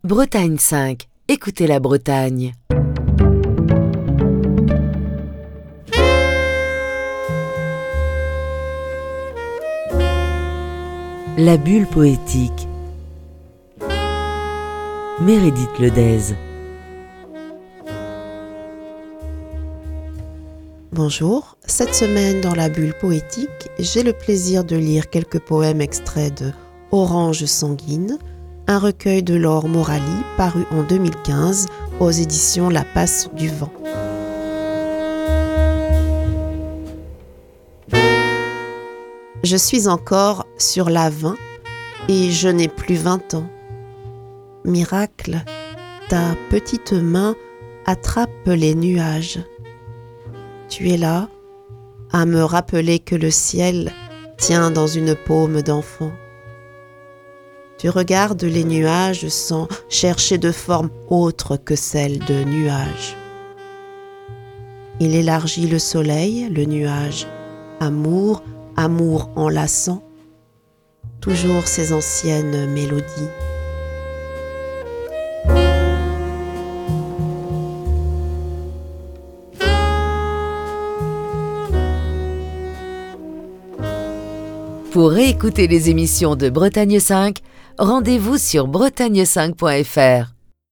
lit quelques poèmes extraits de Orange sanguine, un recueil de Laure Morali, publié en février 2015 aux éditions La Passe du Vent.